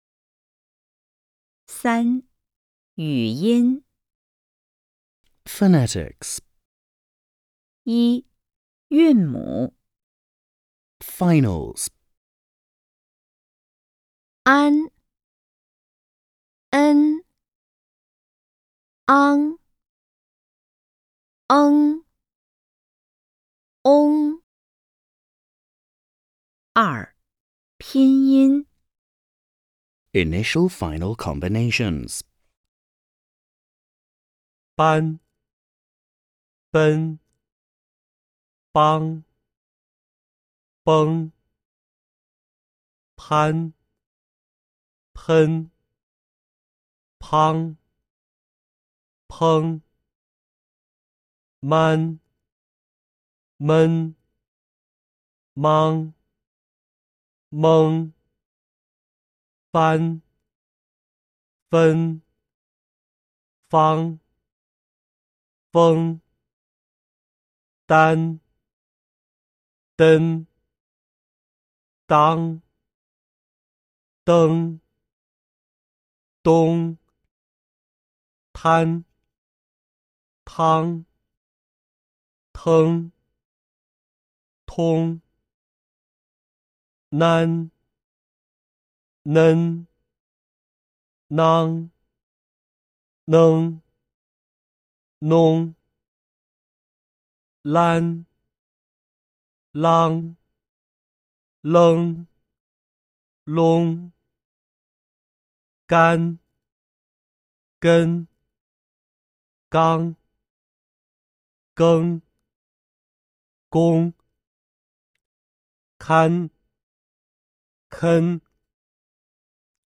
Ấn Mp3 nghe và đọc theo bảng phát âm dưới đây